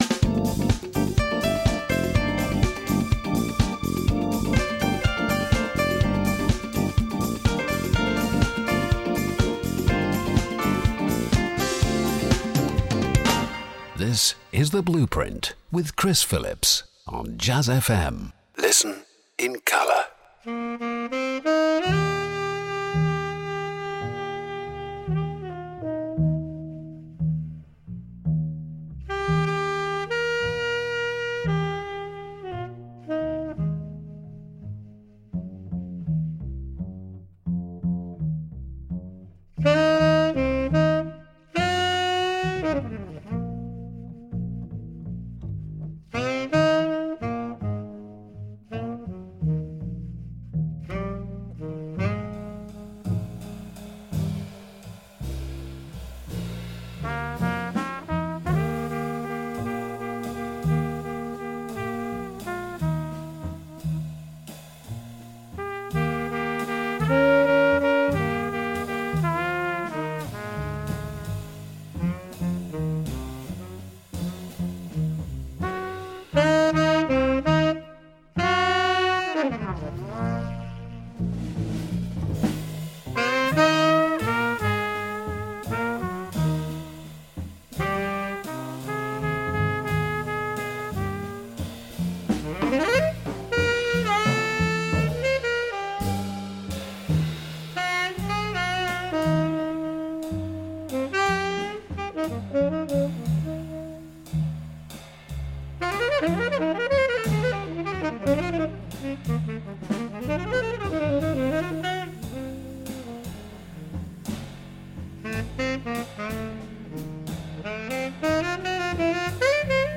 Interviews and Live Sessions